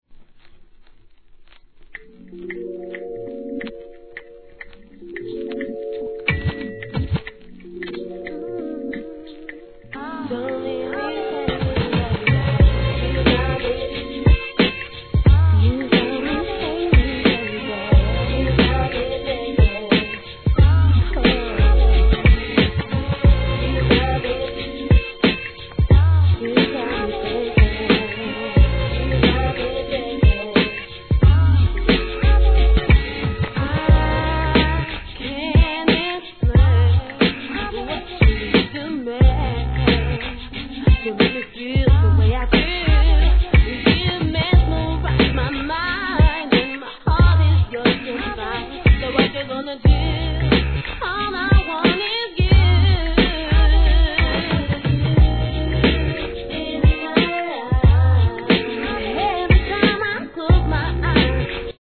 1998年UK R&B!